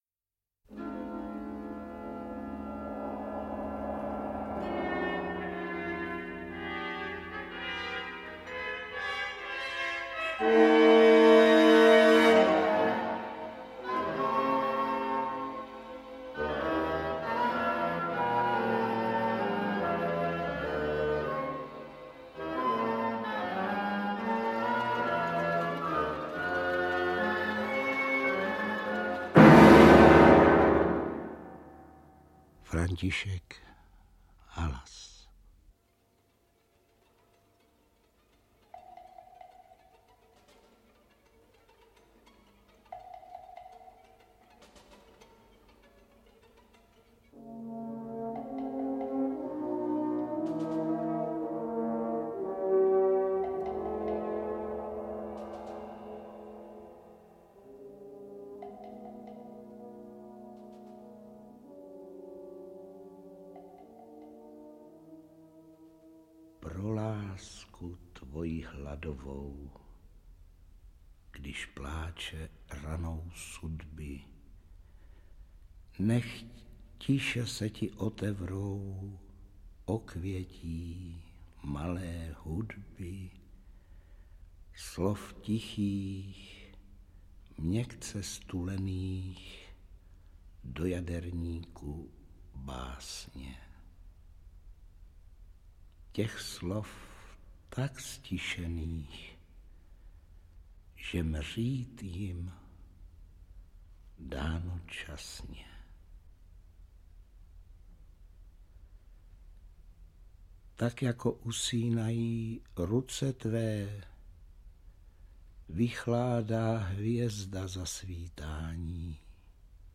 Ženy audiokniha
Ukázka z knihy